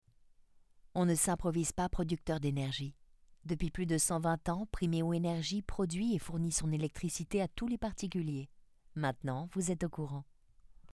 Voix off
30 - 60 ans - Mezzo-soprano